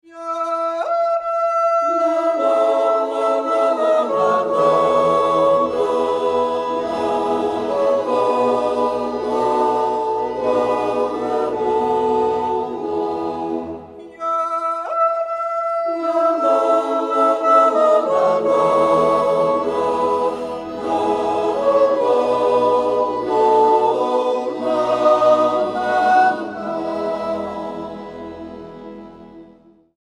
Yodel Choir
Folk music; yodel; yodelling song